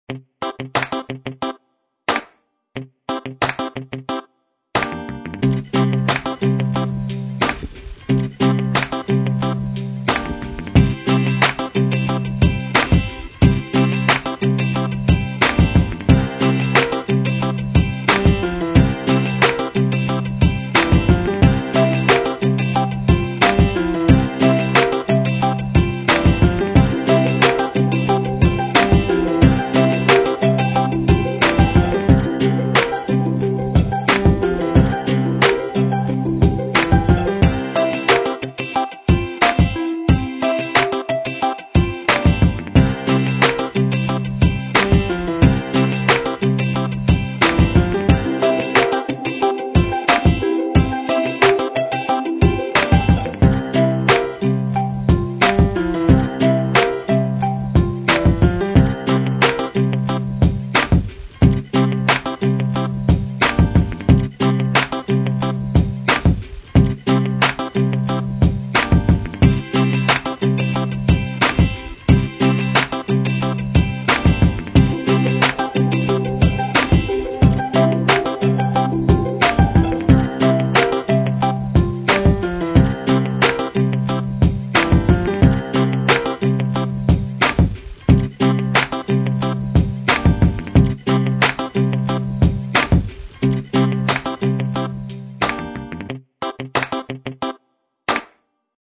描述：这包括三个快乐，令人振奋的音乐作品的视频，商业，电影，网络项目，电影和更多。